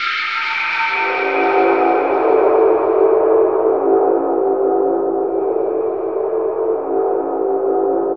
Index of /90_sSampleCDs/Chillout (ambient1&2)/13 Mystery (atmo pads)
Amb1n2_t_pad_a.wav